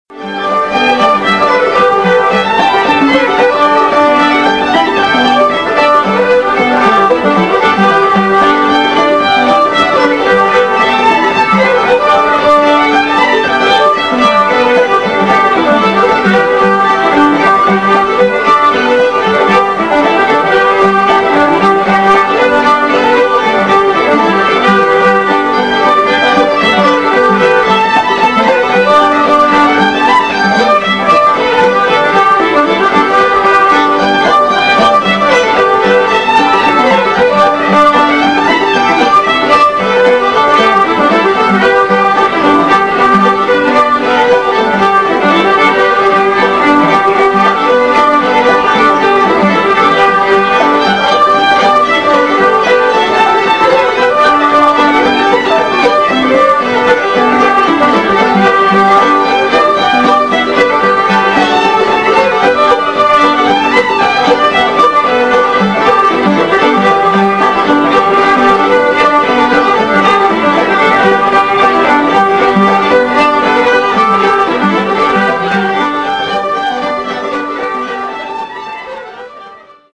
Banjo
Mandolin